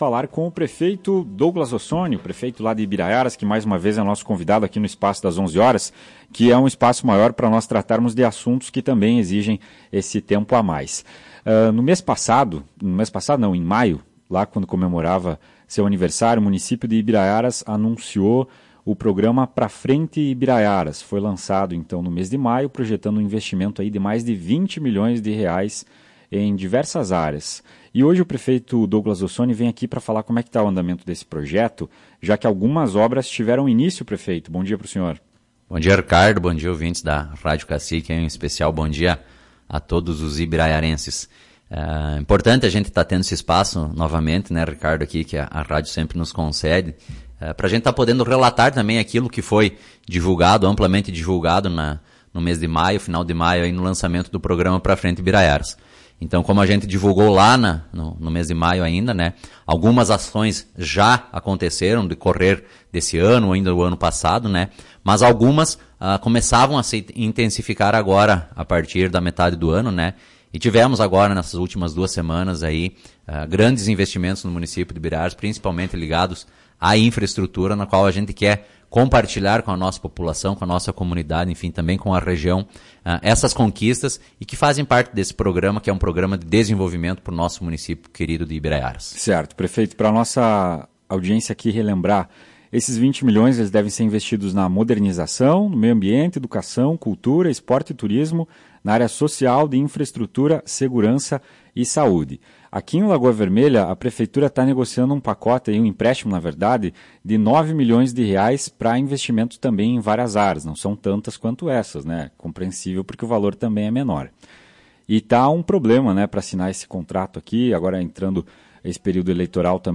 Na manhã desta quarta-feira, 13 de julho, em entrevista à Tua Rádio Cacique, o prefeito Douglas Rossoni (PTB) falou sobre o plano de investimentos e as obras já iniciadas.